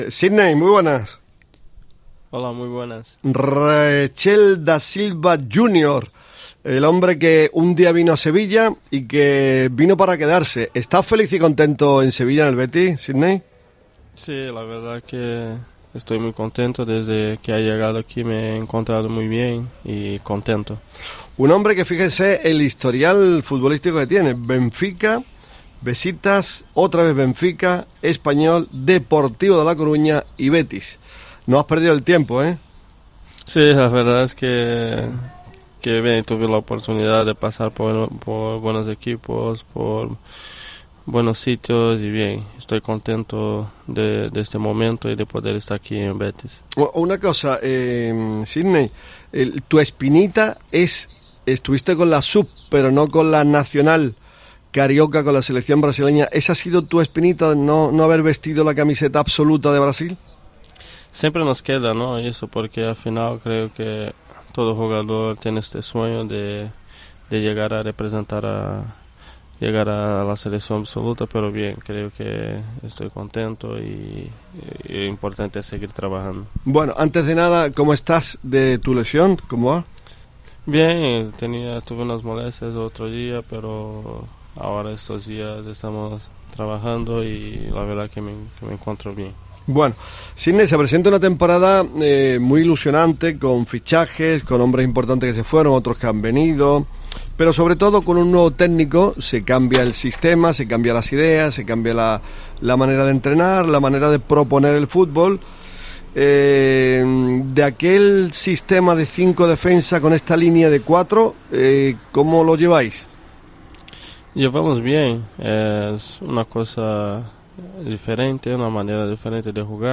desde la Ciudad Deportiva Luis del Sol